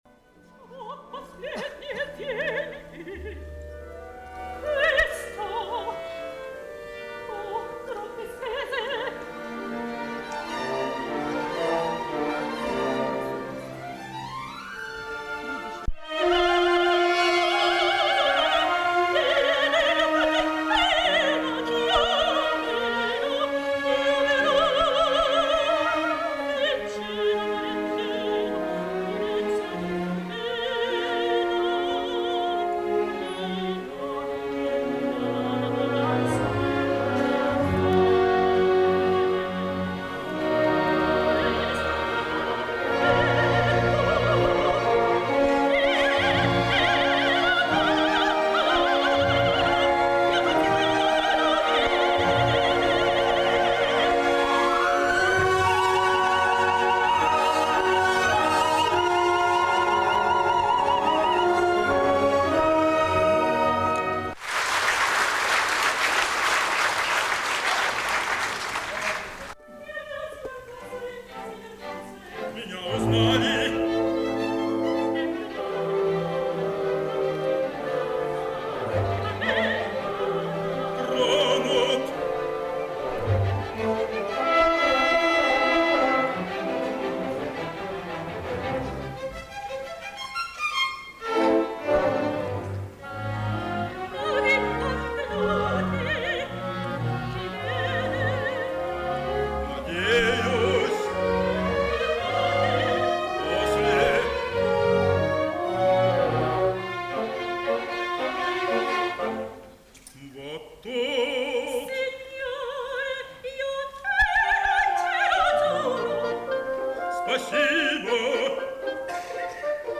“Чио-Чио-Сан” операсынан үзүндү